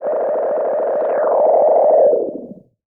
Filtered Feedback 02.wav